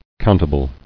[count·a·ble]